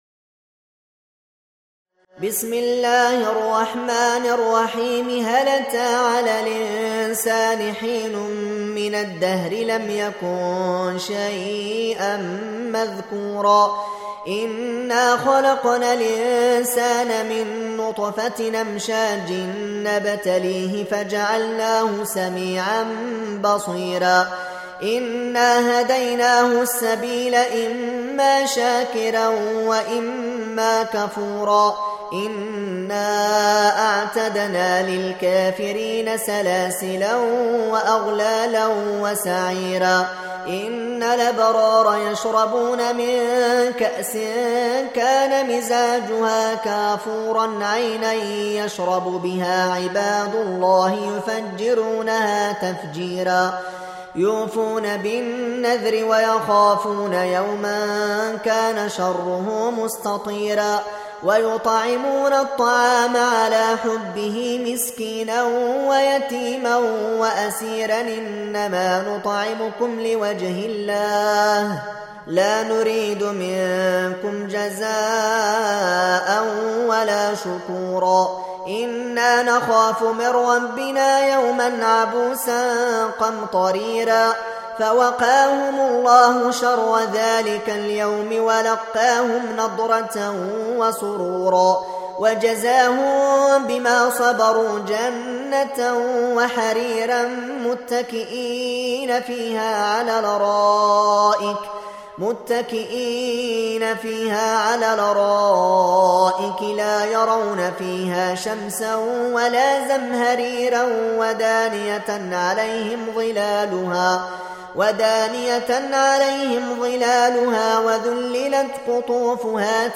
Reciting Murattalah Audio for 76. Surah Al-Insân or Ad-Dahr سورة الإنسان N.B *Surah Includes Al-Basmalah